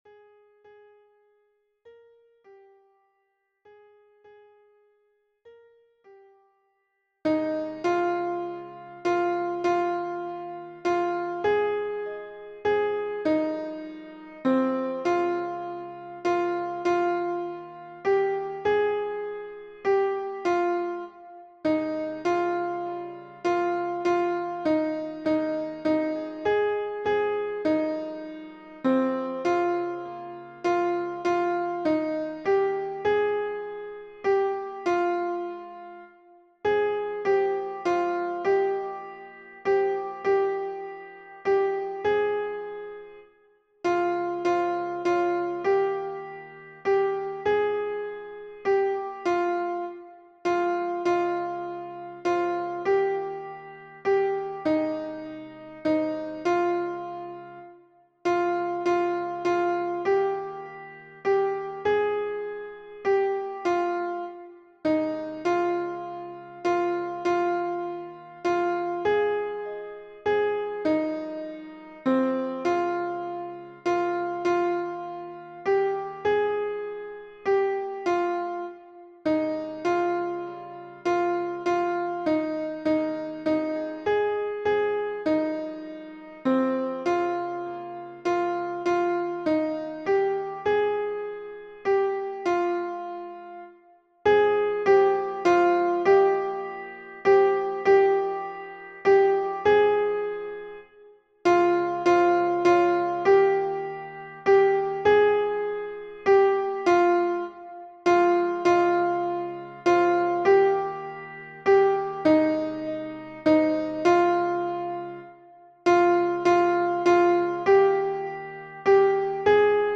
- Œuvre pour chœur à 4 voix mixtes (SATB) + 1 voix soliste
Alto Version Piano